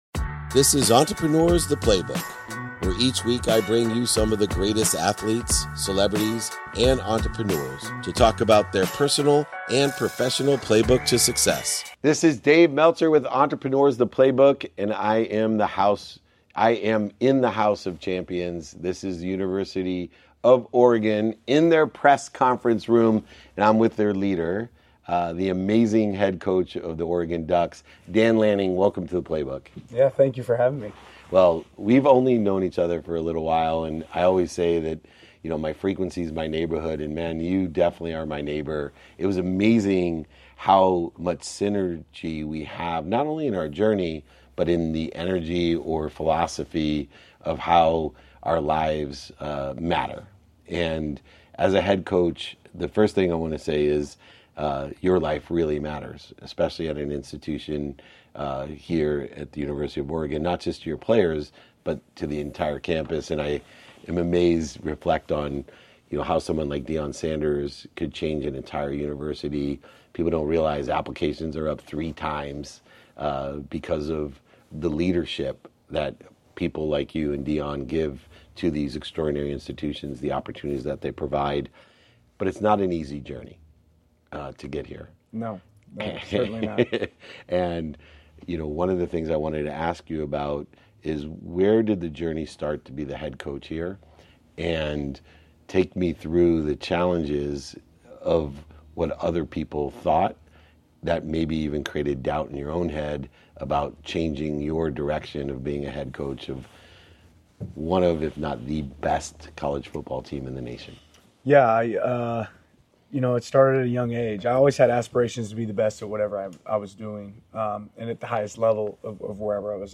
In today’s episode, I sit down in the press conference room at the University of Oregon with Dan Lanning, head coach of the Oregon Ducks. We explore his journey to becoming a head coach, the impactful synergy we share, and how his philosophy enriches not only his team but the entire campus. Dan shares insights from his personal playbook, discussing the drive and resilience that brought him to lead one of the nation's top college football teams, and the ways he empowers young leaders both on and off the field.